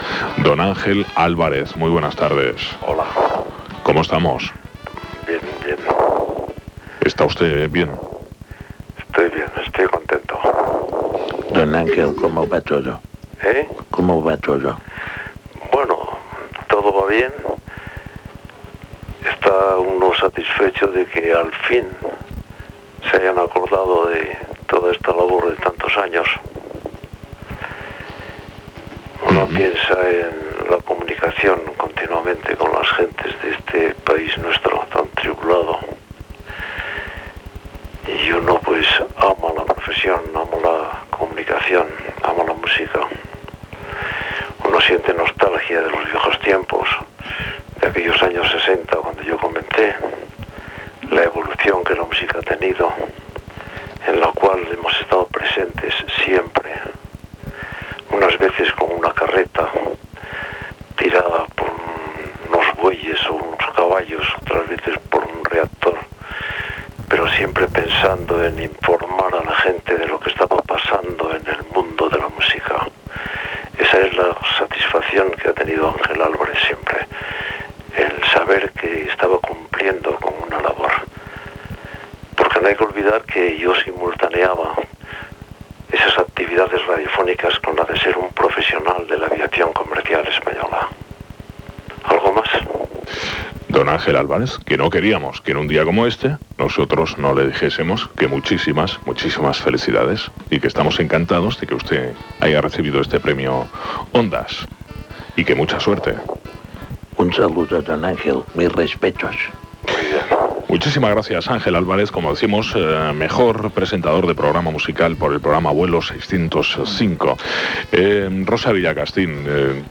Entrevista a Ángel Álvarez.
Entreteniment